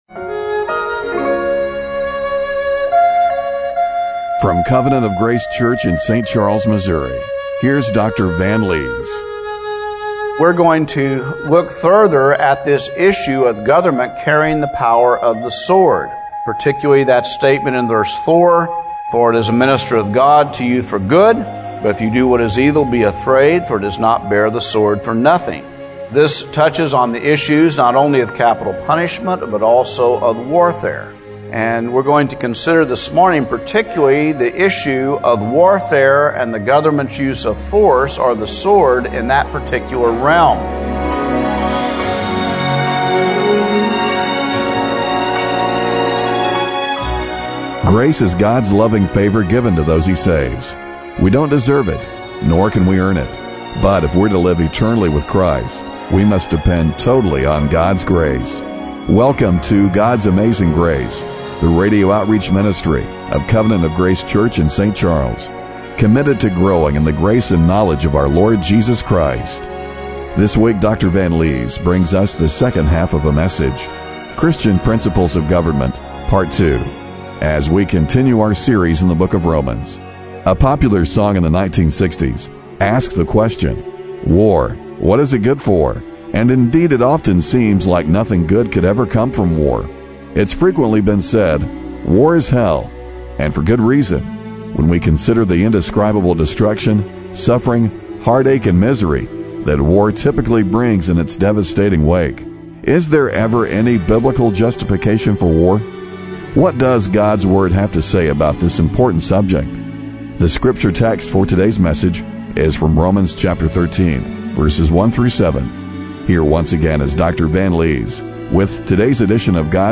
Romans 13:1-7 Service Type: Radio Broadcast What does God's Word have to say about this important subject?